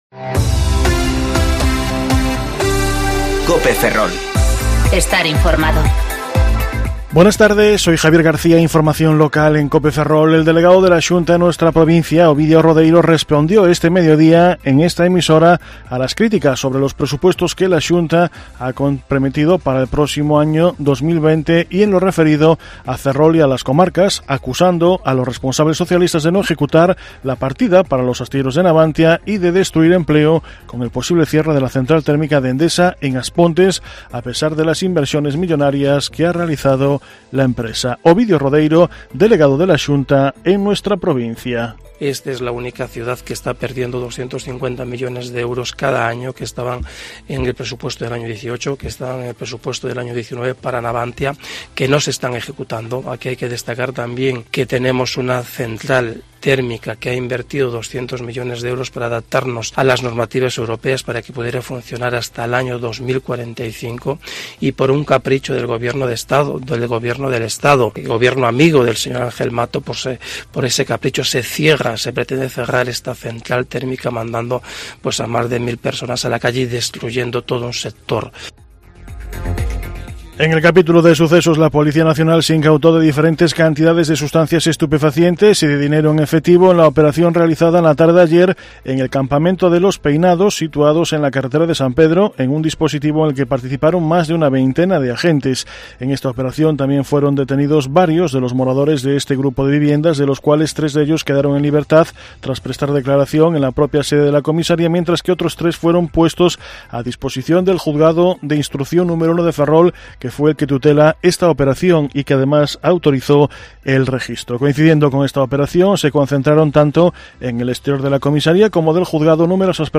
Informativo Mediodía Cope Ferrol 22/10/2019 (De 14.20 a 14.30 horas)